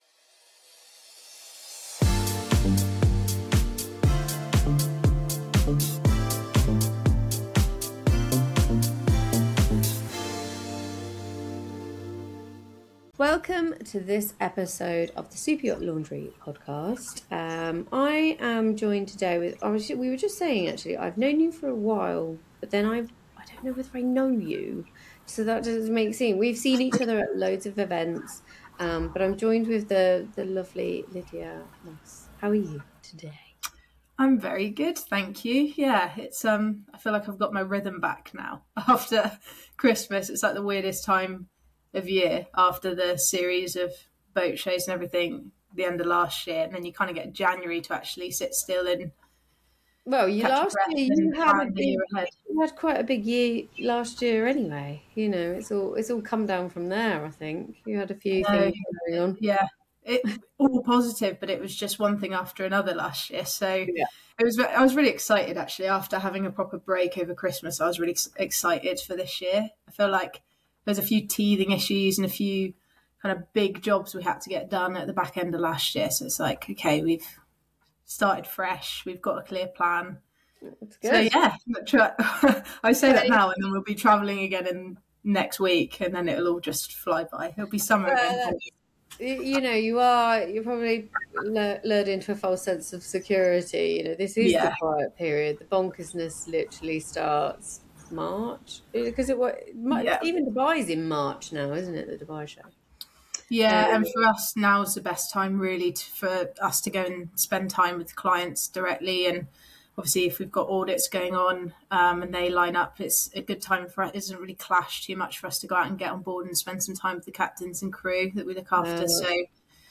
This conversation offers insight into how the industry is evolving and how staying curious, connected and adaptable is key to thriving within it.